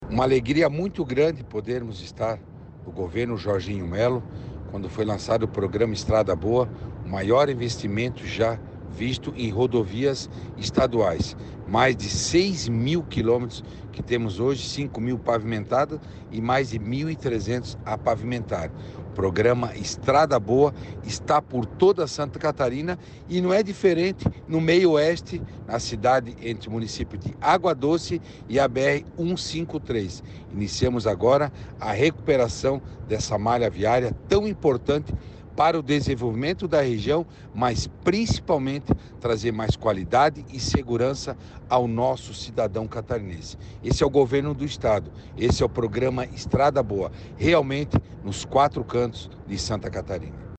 O secretário da Infraestrutura e Mobilidade (SIE), Jerry Comper, vistoriou o andamento das obras esta semana:
SECOM-Sonora-secretario-da-Infraestrutura-26.mp3